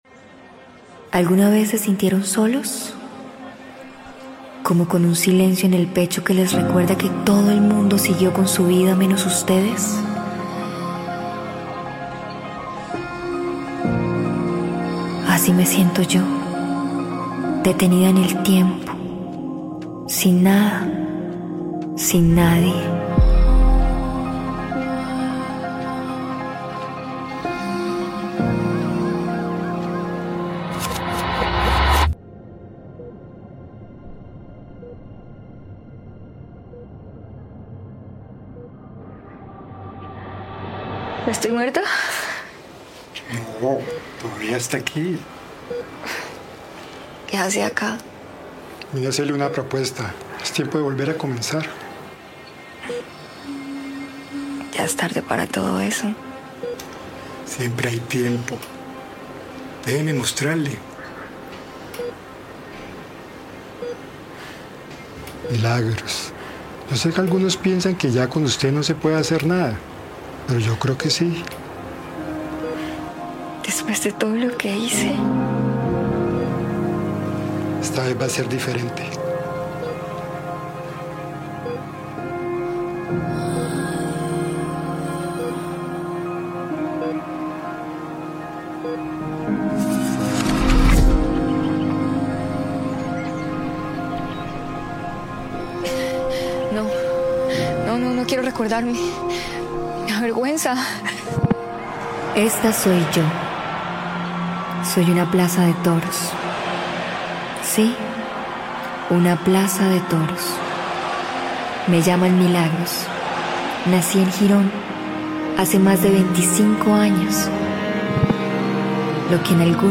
Relato simbólico Arena Bonita, nuevo centro de eventos en Santander
En este trabajo audiovisual, la estructura cobra voz propia bajo el nombre de Milagros, una metáfora que refleja el paso del tiempo, el olvido y la posibilidad de volver a empezar.